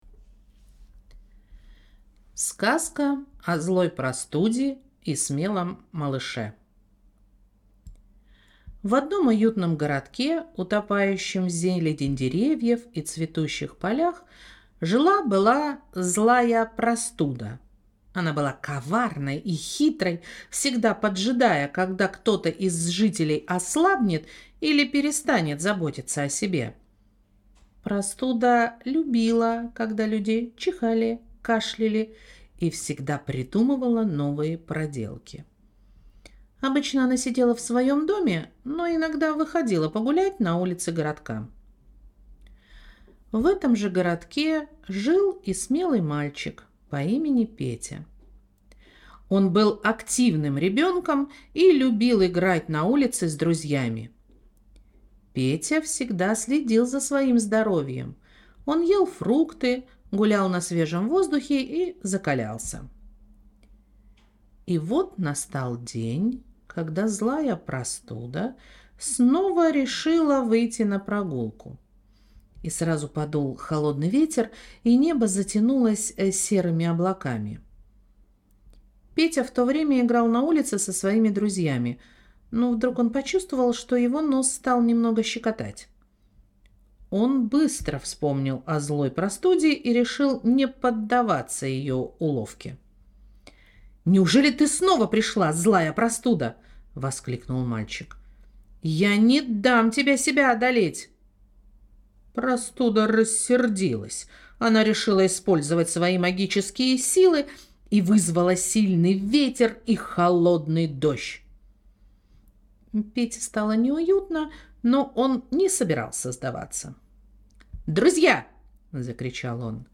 Аудиосказки